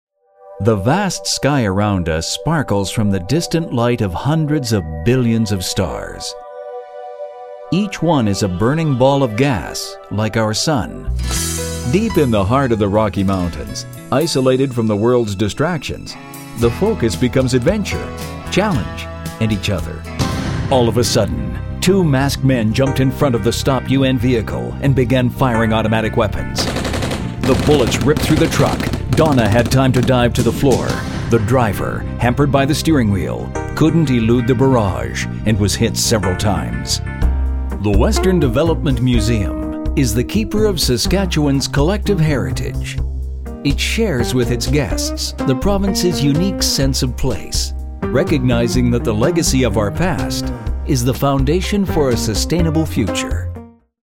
Voice over talent with audio production studio specializing in tv and radio commercial production, multi-media narration and character voices
Kein Dialekt
Sprechprobe: eLearning (Muttersprache):